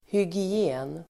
Ladda ner uttalet
Uttal: [hygi'e:n]